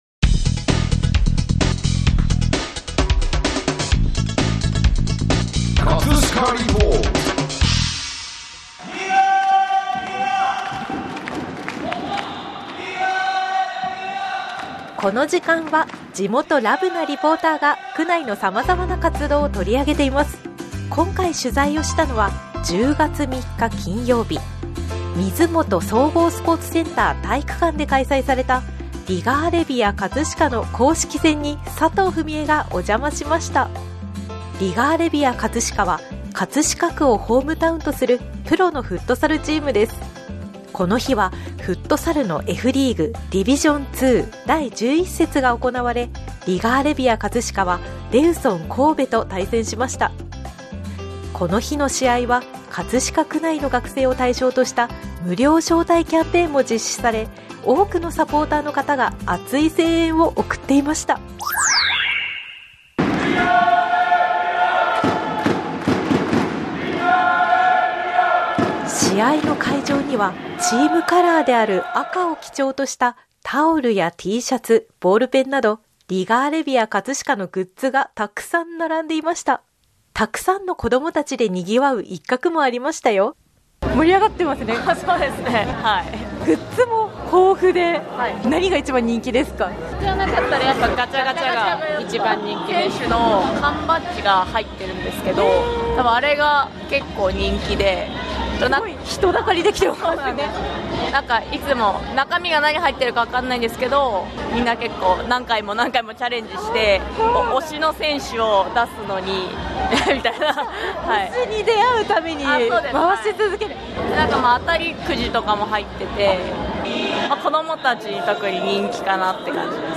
皆さんの熱い想いもインタビューしていますのでぜひお聴きください！